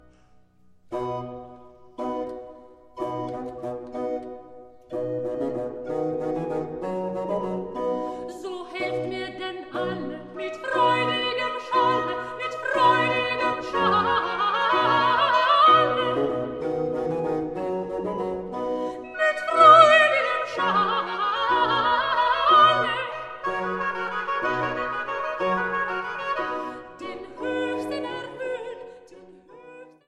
Kirchweihkantaten
Sopran
Orgel und Leitung